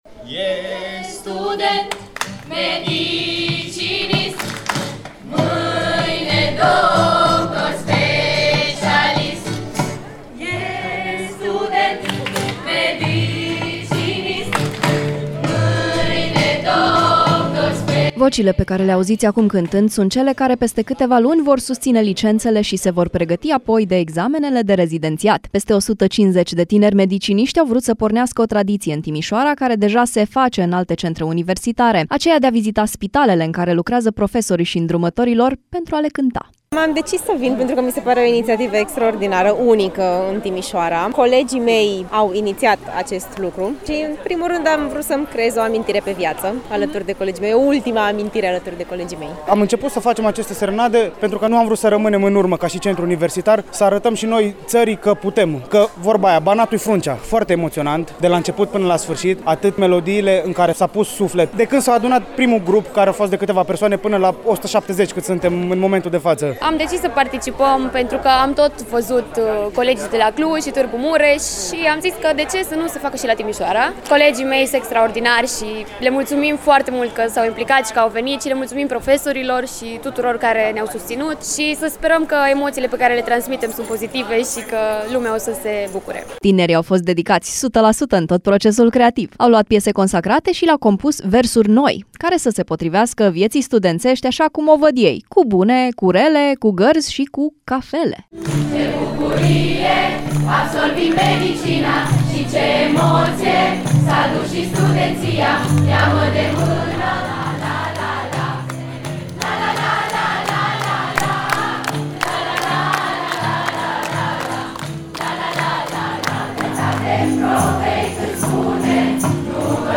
Tinerii vizitează de spitalele în care lucrează profesorii și îndrumătorii lor pentru a le cânta în semn de mulțumire.
Au luat piese consacrate și l-a compus versuri noi, care să se potrivească vieții studențești așa cum o văd ei cu bune, cu rele, cu gărzi și cu cafele.